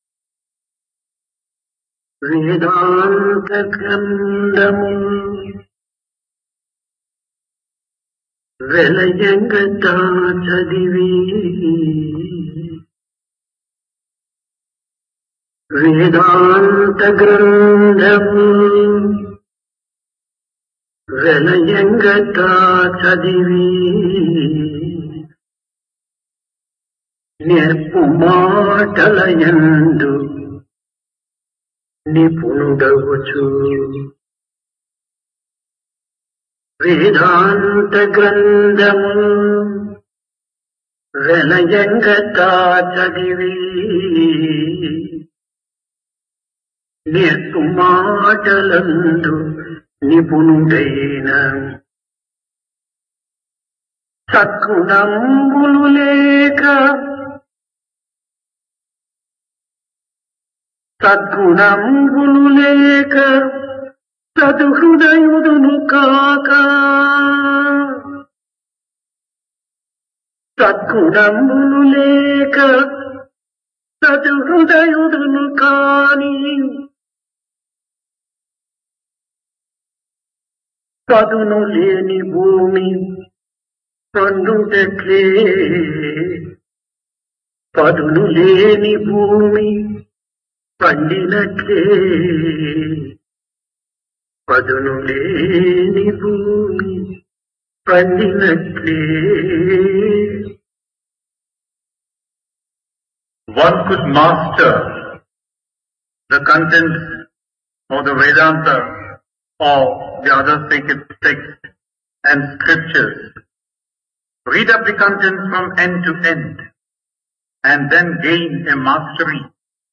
Divine Discourse of Bhagawan Sri Sathya Sai Baba
Occasion: Dasara
Place Prasanthi Nilayam